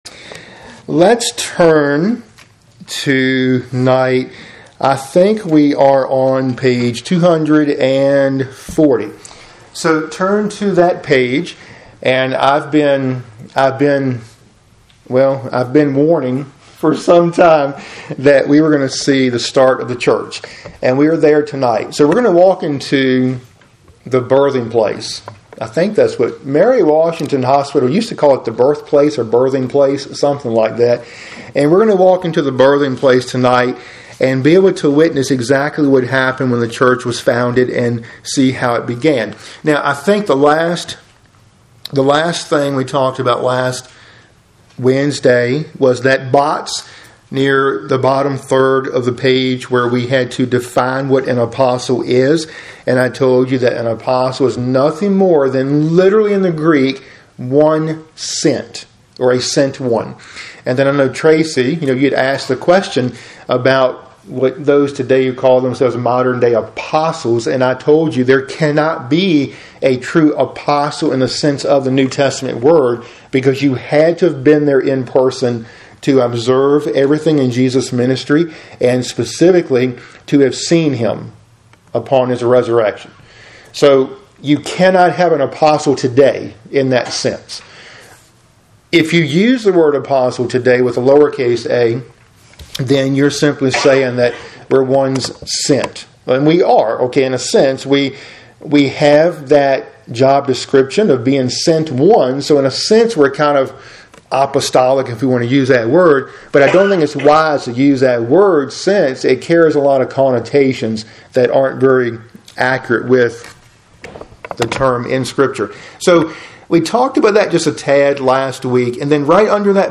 Midweek Bible Study – Lesson 49 (cont.)